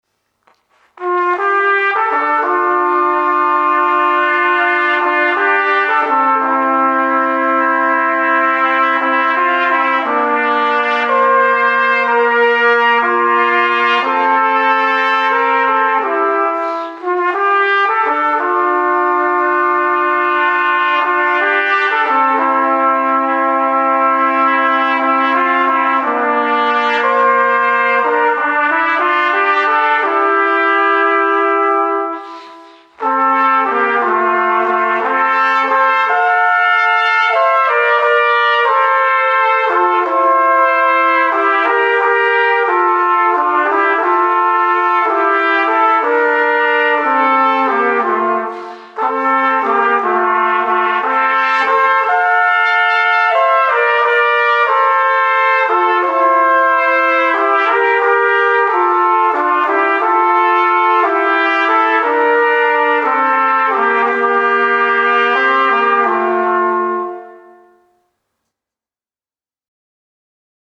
Gattung: für zwei Trompeten oder Flügelhörner